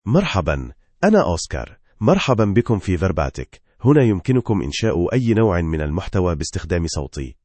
Oscar — Male Arabic (Standard) AI Voice | TTS, Voice Cloning & Video | Verbatik AI
MaleArabic (Standard)
Oscar is a male AI voice for Arabic (Standard).
Voice sample
Oscar delivers clear pronunciation with authentic Standard Arabic intonation, making your content sound professionally produced.